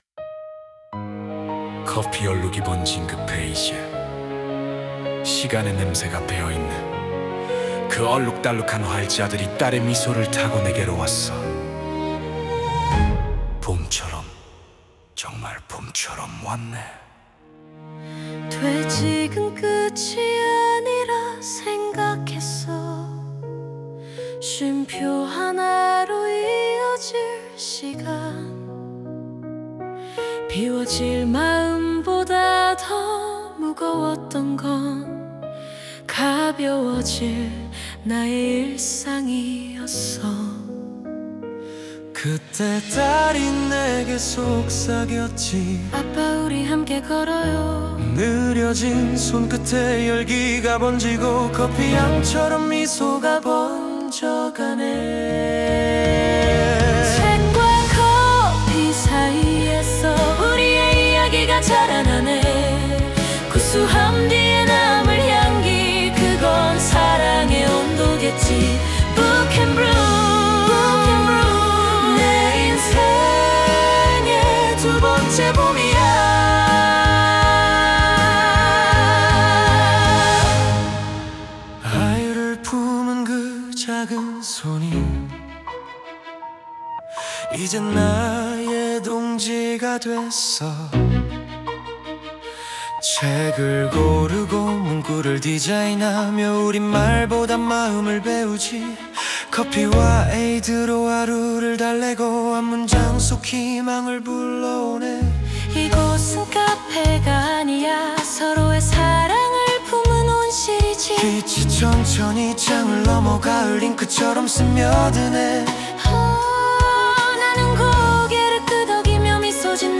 장문의 프롬프트를 작성해 음원을 생성했다.
원하던 재즈의 결이 나오지 않아 여러 차례 수정을 거듭했고, 결국 가사를 처음부터 통째로 다시 써야 했다.